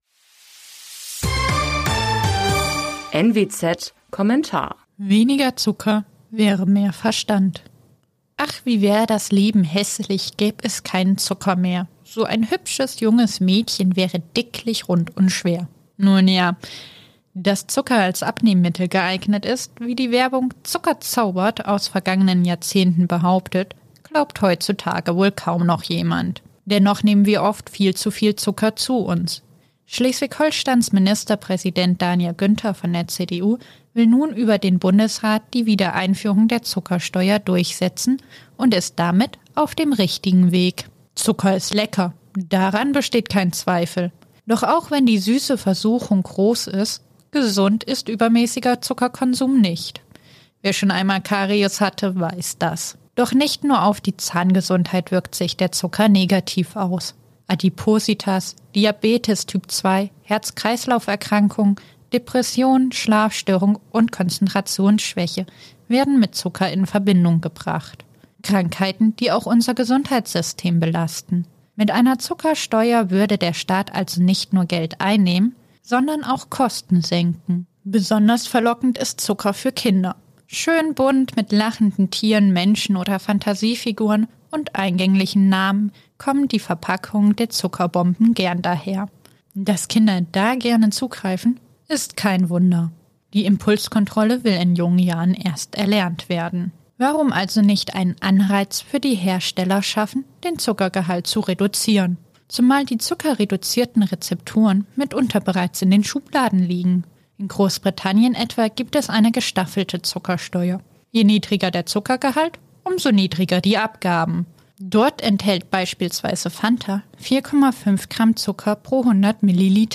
Genres: News, News Commentary